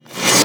VEC3 Reverse FX
VEC3 FX Reverse 27.wav